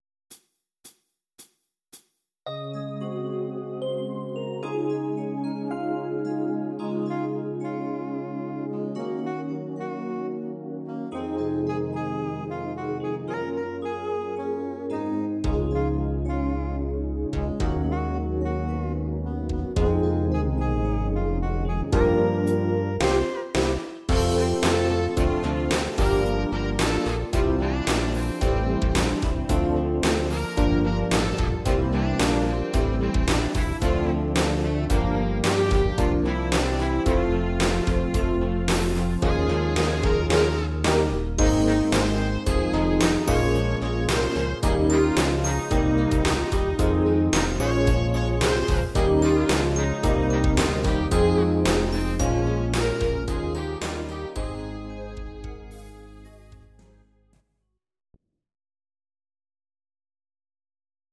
These are MP3 versions of our MIDI file catalogue.
Please note: no vocals and no karaoke included.
Film theme